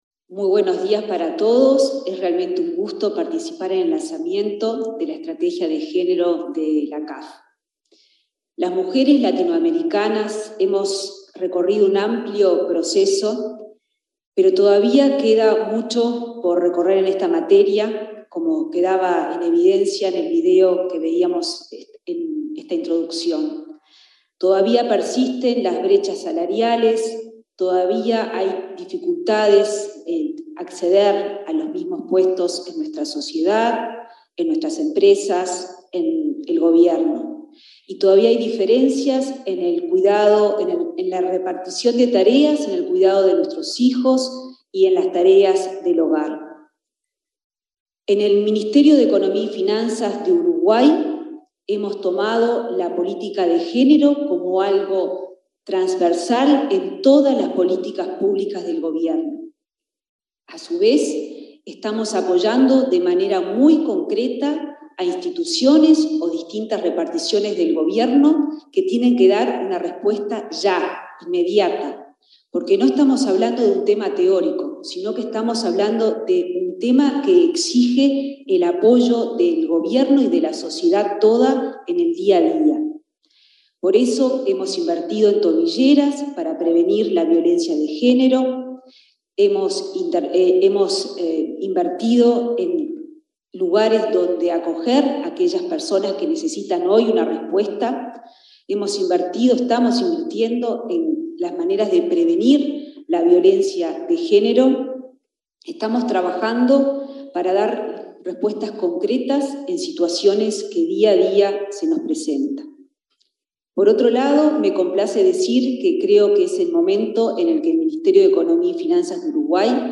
Exposición de la ministra de Economía, Azucena Arbeleche
La ministra de Economía, Azucena Arbeleche, expuso este martes 8 en el acto de lanzamiento de la Estrategia CAF-Banco de Desarrollo de América Latina